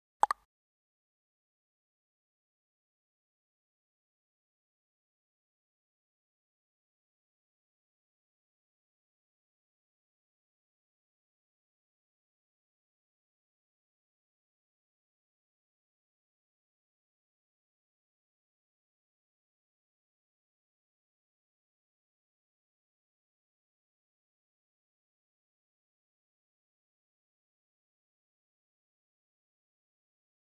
Звуки уведомлений о сообщениях